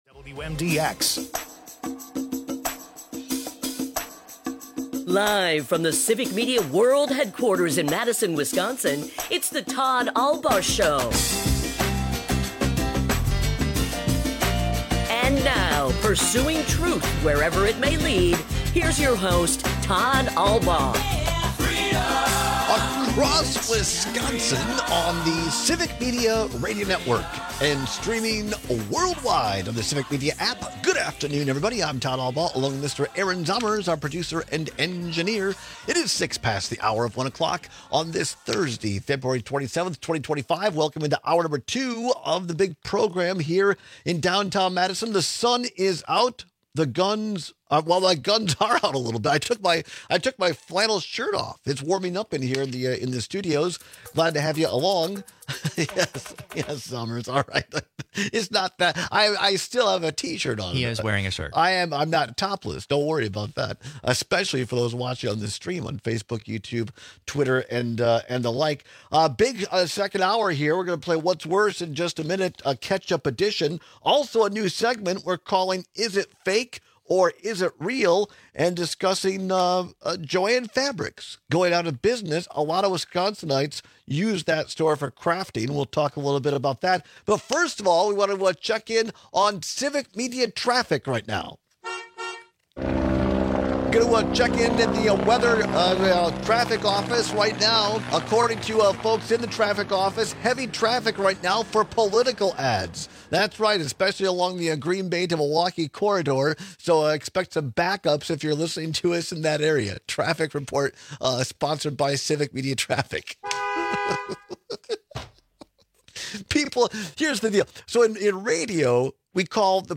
The specialty craft store chain Joann is declaring bankruptcy and closing down all of their locations. We take calls on this unfortunate series of events.